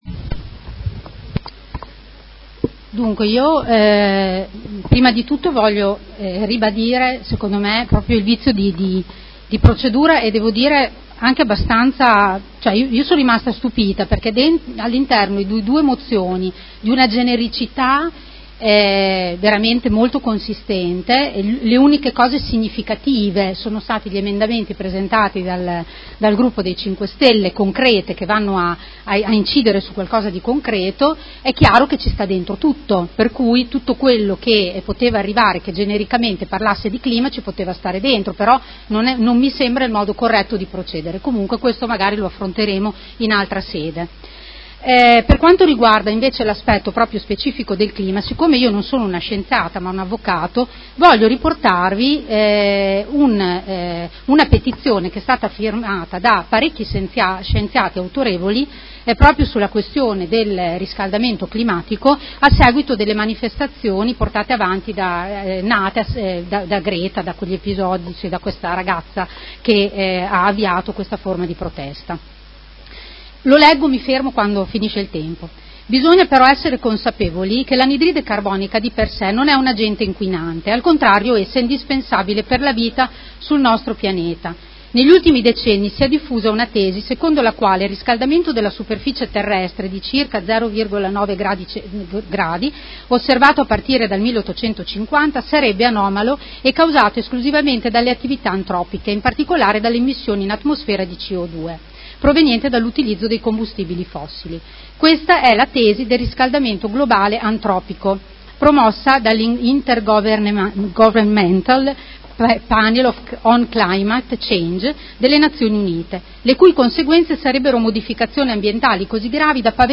Seduta del 25/07/2019 Dibattito. Mozione nr. 187936 - Mozione nr. 221209 ed emendamenti